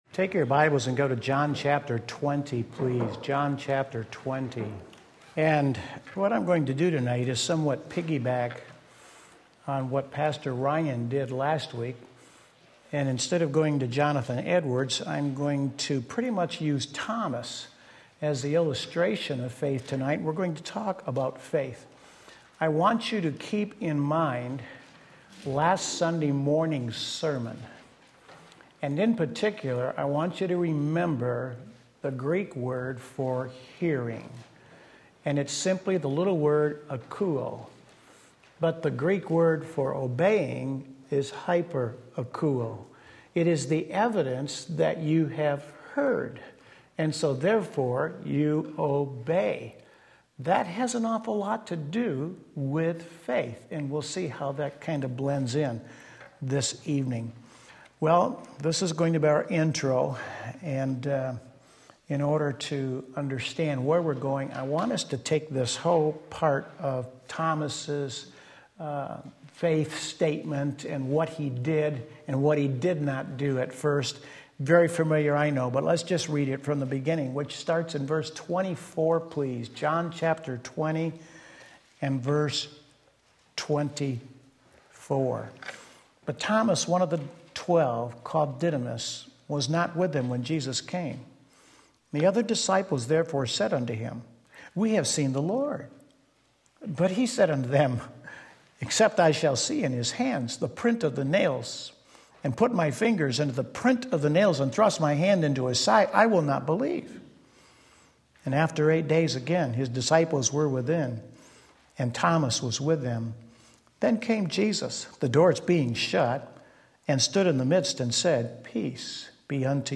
Sermon Link
Romans 14:23 Wednesday Evening Service